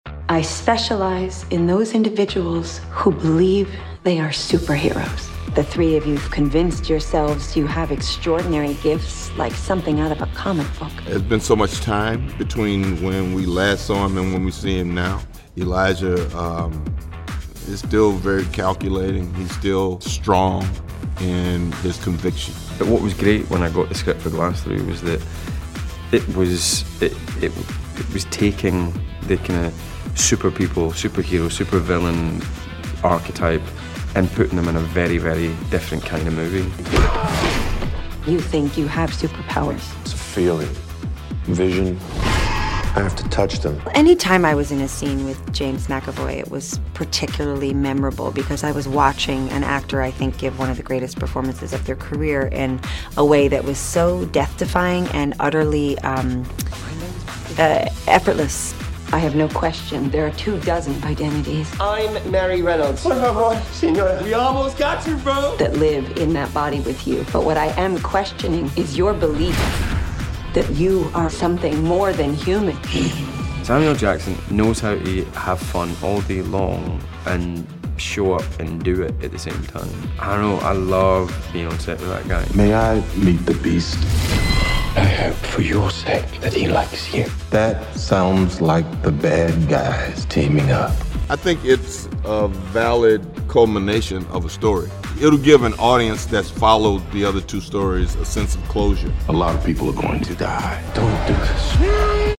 The stars of the movie tell us about the film that offers a different and unique perspective of a superhero and what it was like working with one another. The third and final installment hits theaters February 18th.